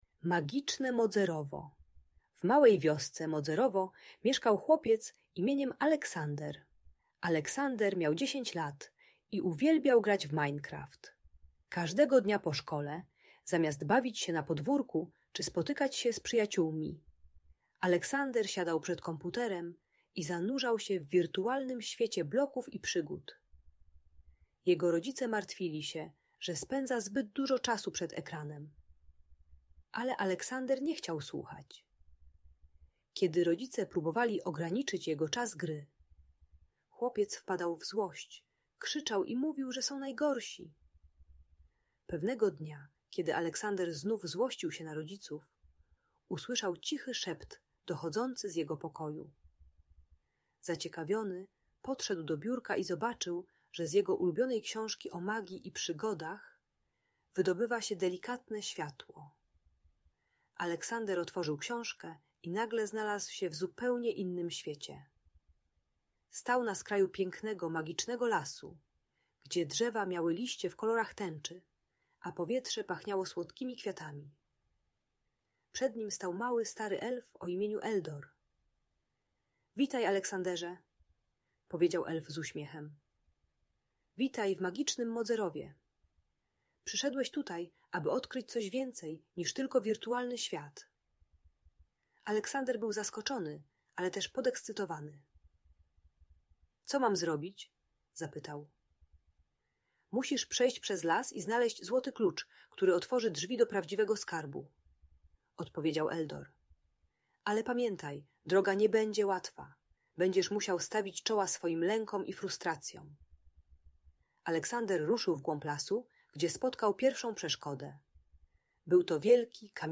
Magiczne Modzerowo - Audiobajka